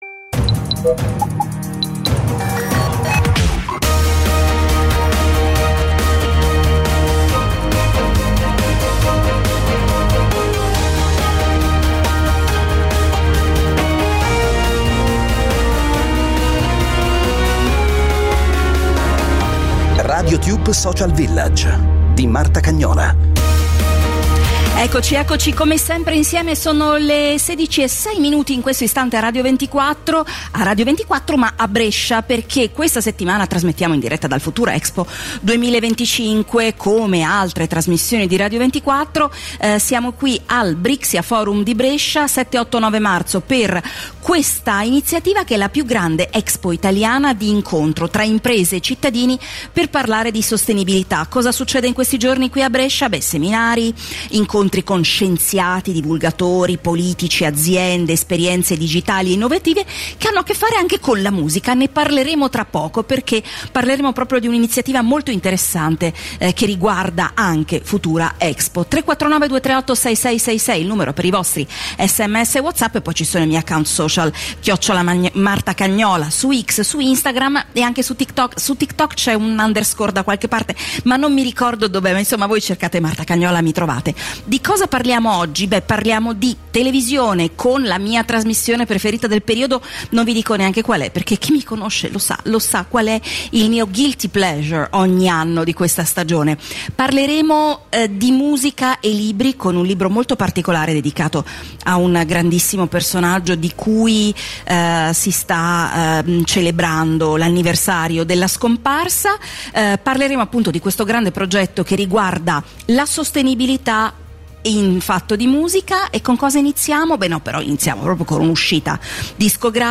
Radiotube - Social Village - Radiotube Social Village – In diretta da Futura Expo 2025